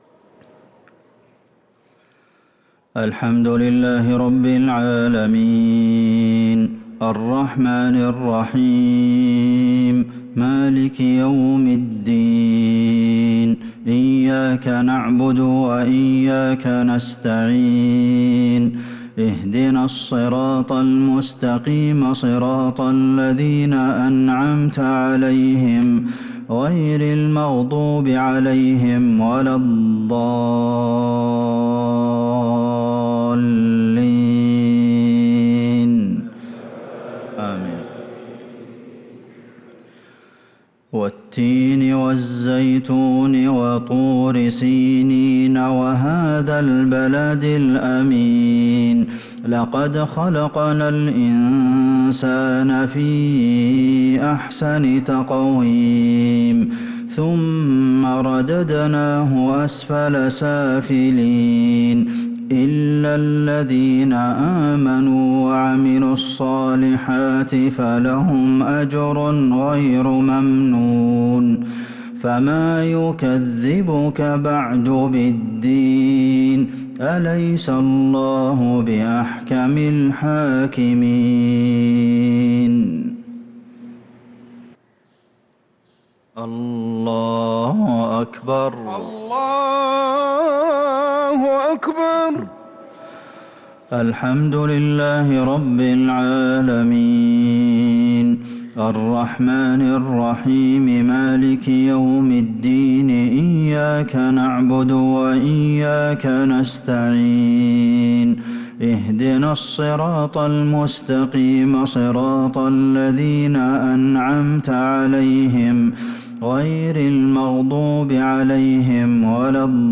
صلاة المغرب للقارئ عبدالمحسن القاسم 15 ربيع الآخر 1444 هـ
تِلَاوَات الْحَرَمَيْن .